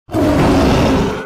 Harimau_Suara.ogg